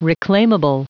Prononciation du mot reclaimable en anglais (fichier audio)
Prononciation du mot : reclaimable